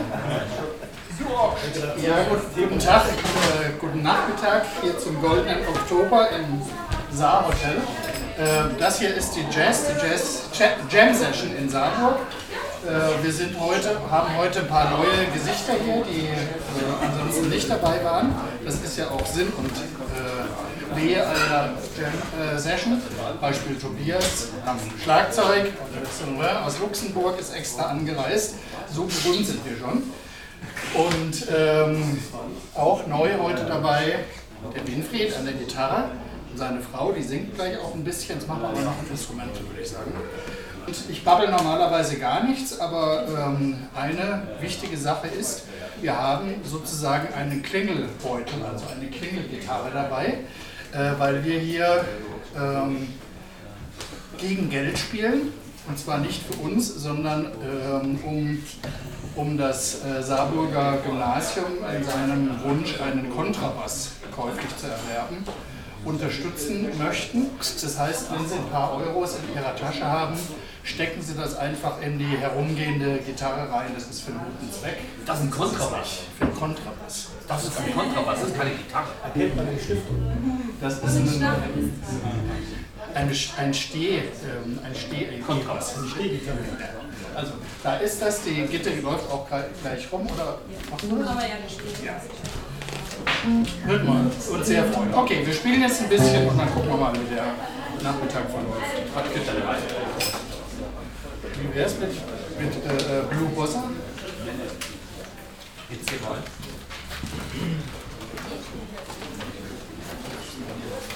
02 - Ansage.mp3